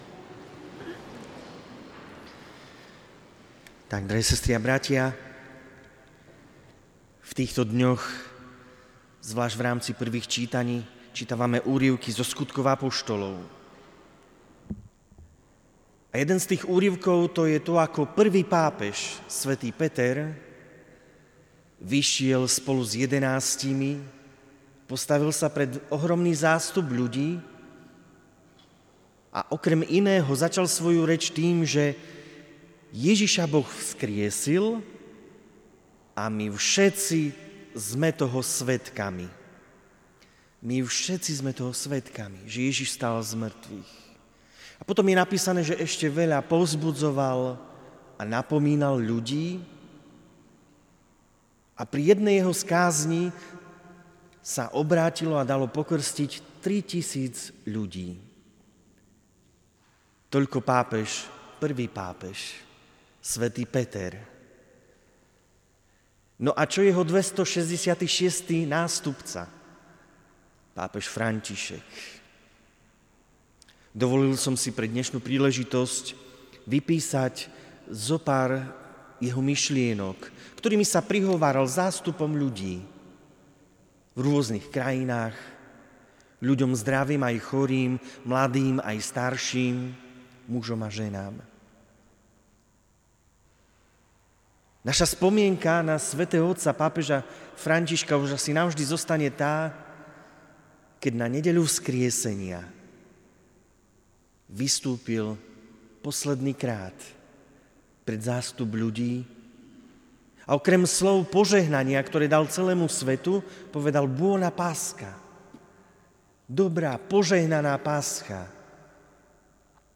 Aprílová pobožnosť k bl. Metodovi bola výnimočnou nie len tým, že sa konala vo Svetlom týždni.